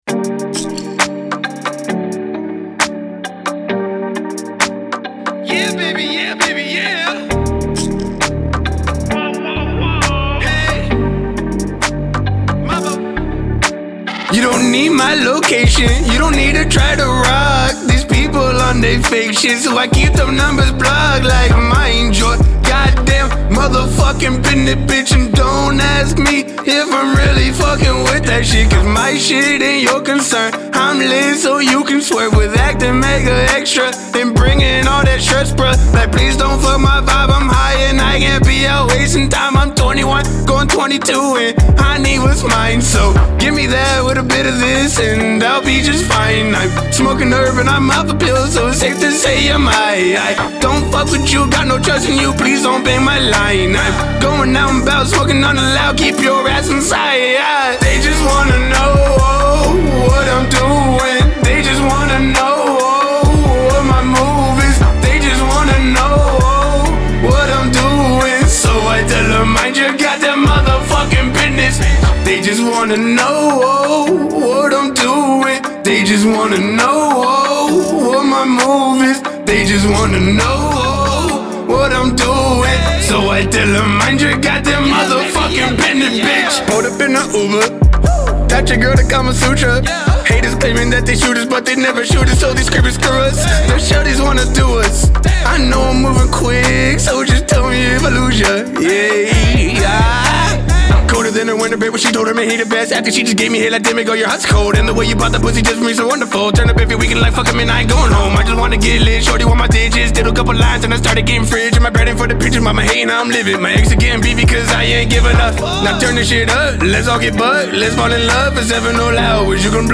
Seattle Hip Hop.